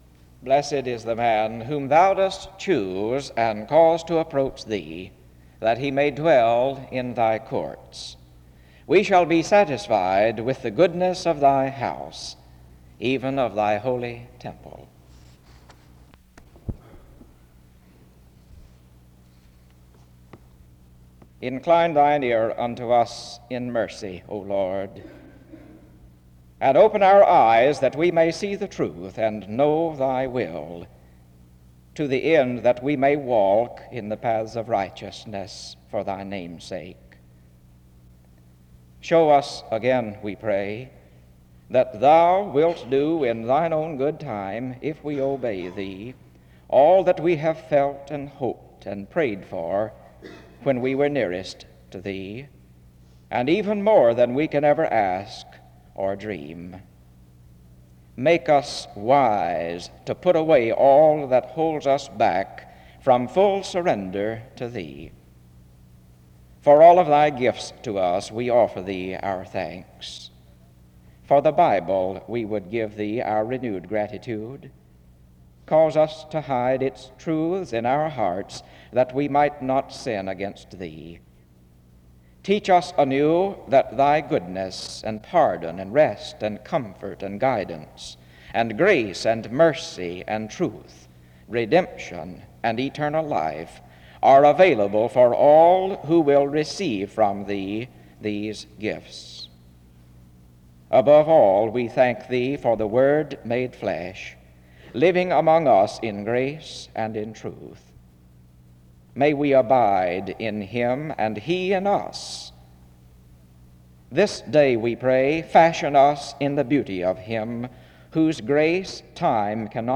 Download .mp3 Description The service begins with the reading of Psalm 65:4 (00:00-00:20) and prayer (00:21-02:32).
Preaching